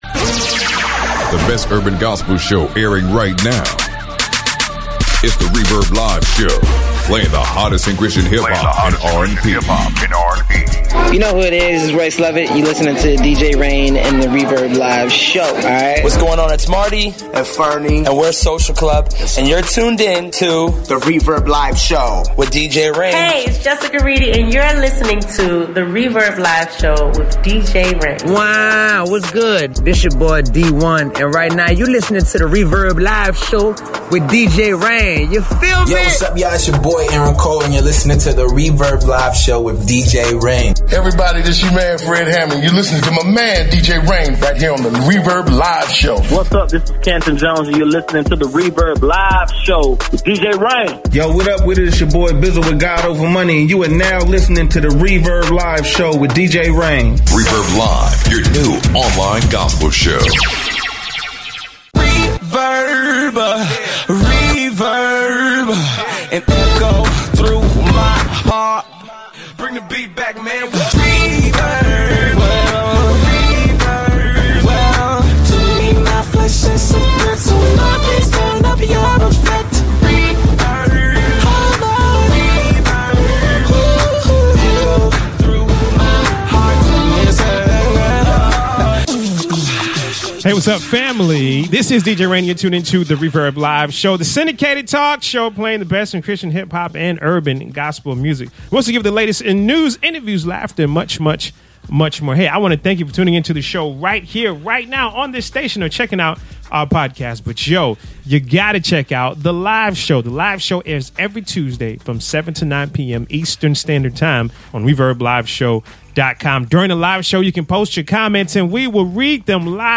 We interview real life cancer survivors